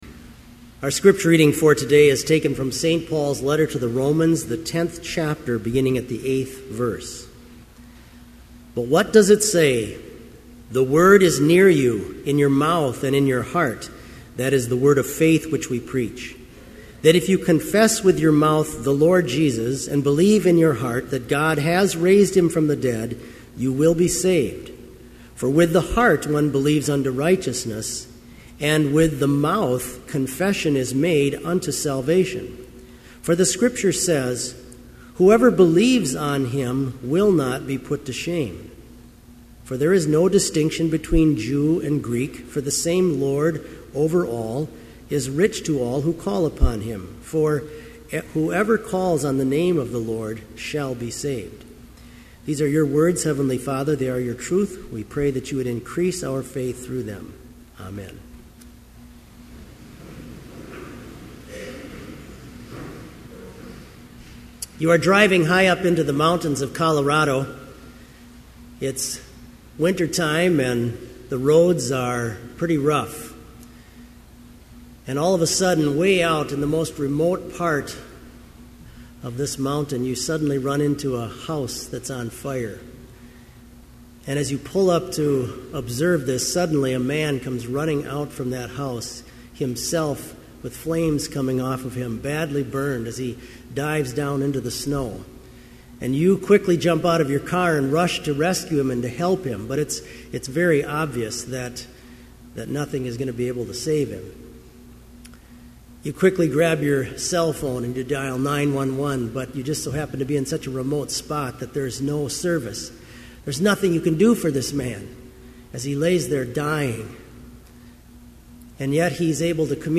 Complete service audio for Chapel - October 27, 2011